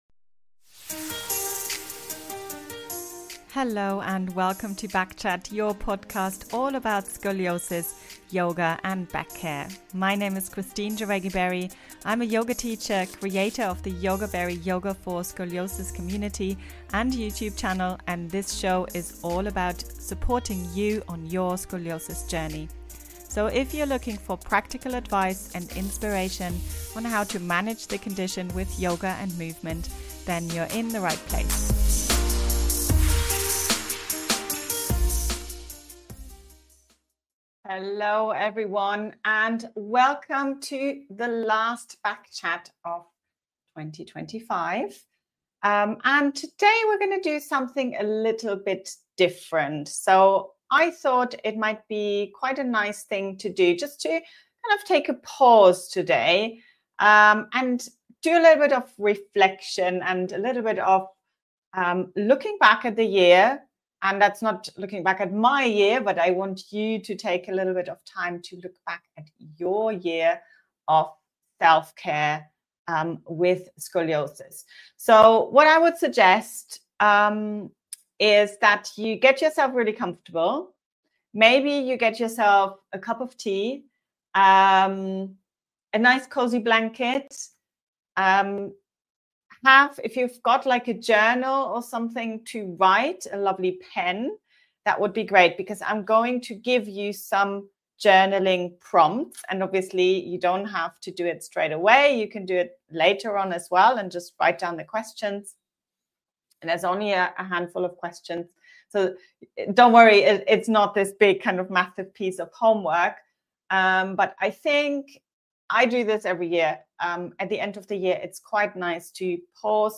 A slower-paced, reflective session to honour what your body has carried this year. You’ll guide listeners through a few journalling prompts to help them reconnect with their body and set gentle intentions for the new year.